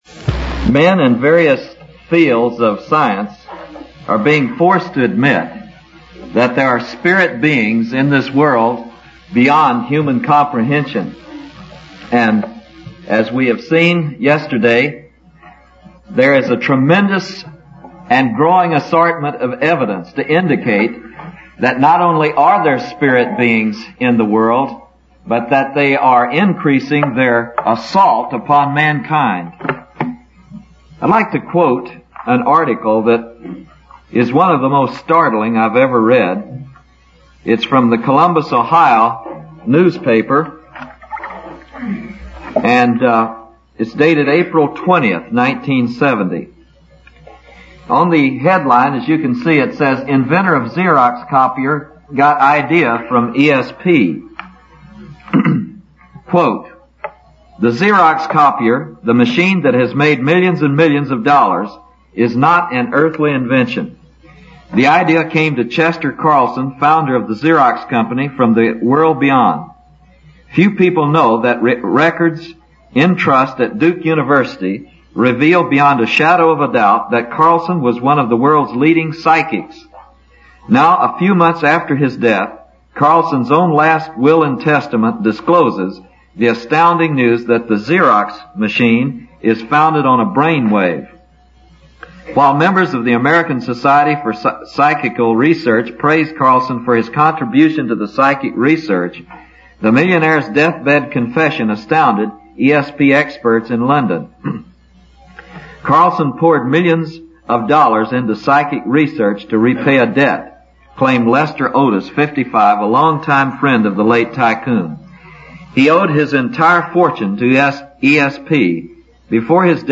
In this sermon, the speaker discusses the existence of a powerful and real spiritual being behind the conflicts and suffering in the world.